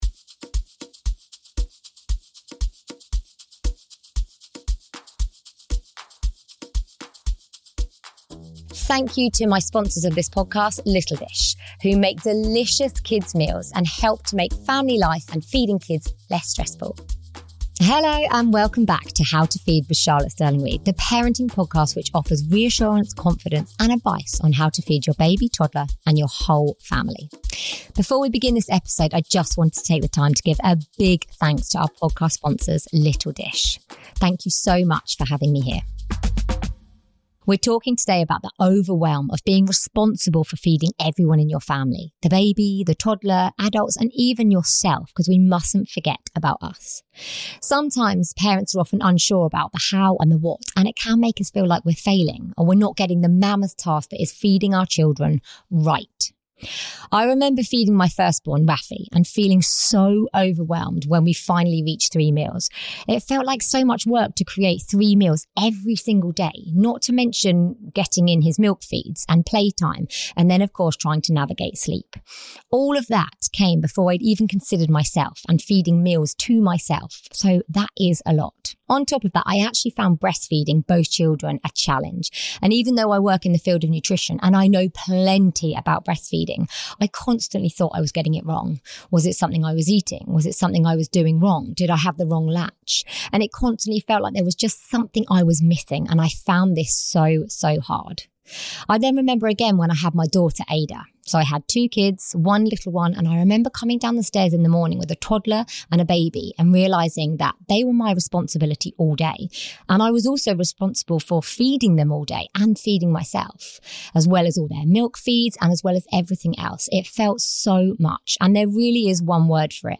This podcast is sponsored by Little Dish and recorded at their HQ.